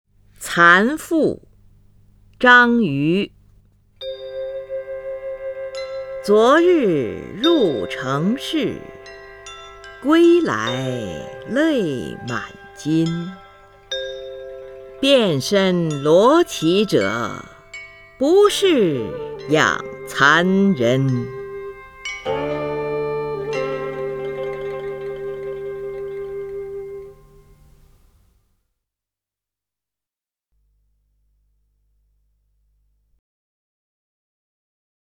林如朗诵：《蚕妇》(（北宋）张俞) (右击另存下载) 昨日入城市， 归来泪满巾。
（北宋）张俞 文选 （北宋）张俞： 林如朗诵：《蚕妇》(（北宋）张俞) / 名家朗诵欣赏 林如